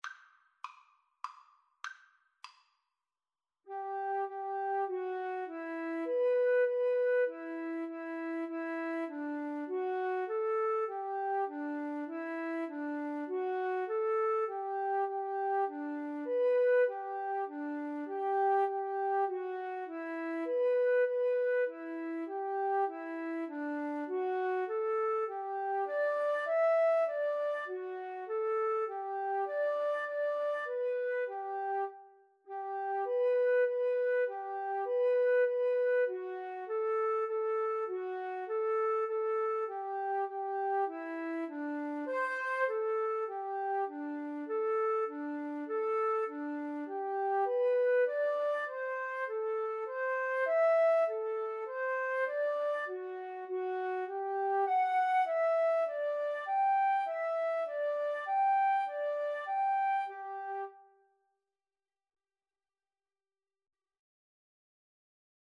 3/4 (View more 3/4 Music)
G major (Sounding Pitch) (View more G major Music for Flute Duet )
Flute Duet  (View more Intermediate Flute Duet Music)